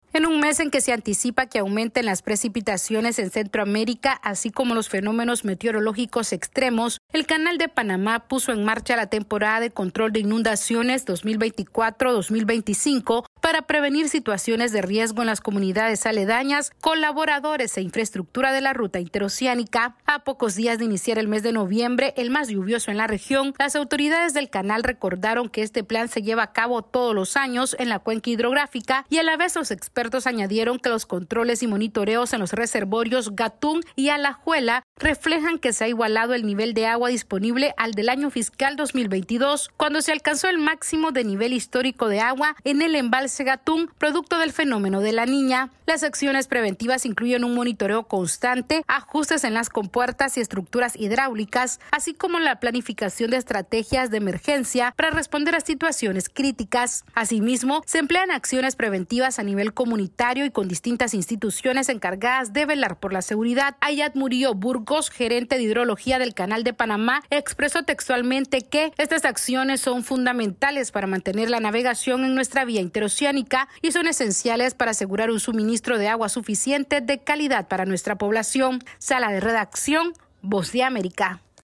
El Canal de Panamá activó la temporada de control de inundaciones para proteger a las comunidades cercanas y la infraestructura de la ruta interoceánica. Esta es una actualización de nuestra Sala de Redacción...